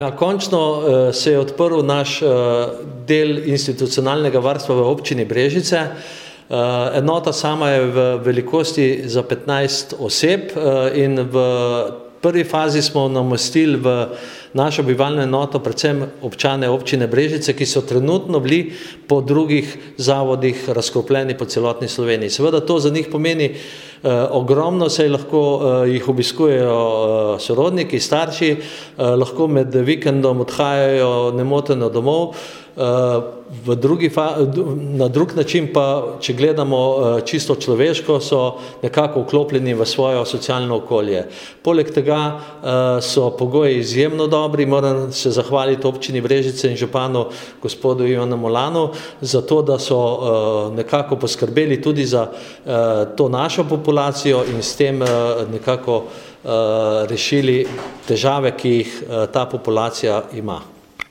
Izjava –